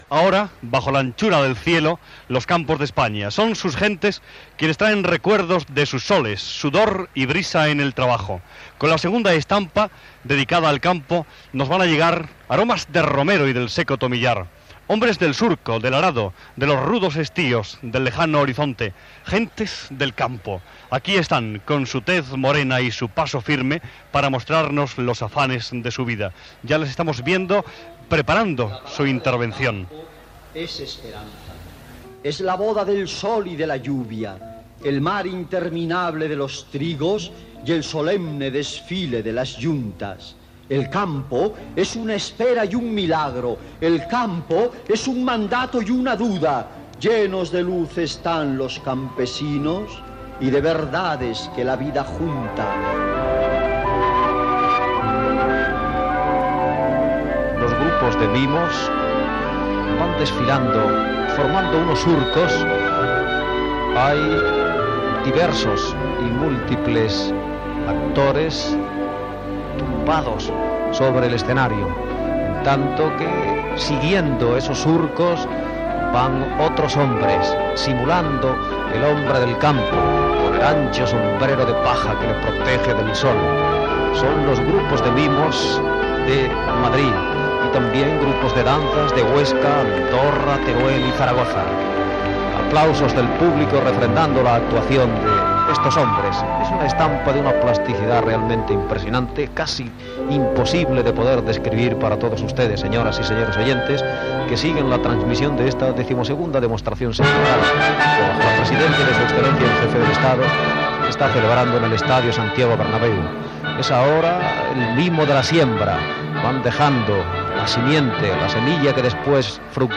Transmissió de la XII Demostración Sindical des de l'Estadio Santiago Bernabeu de Madrid
Informatiu